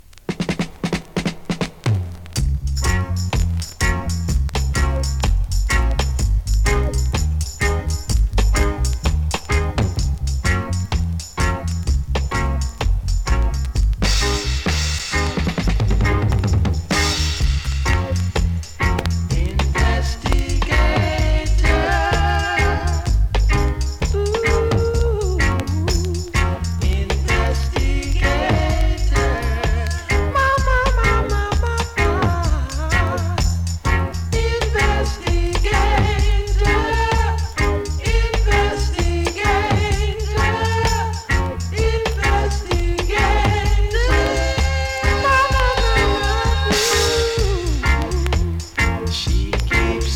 2022 NEW IN!!SKA〜REGGAE!!
スリキズ、ノイズ比較的少なめで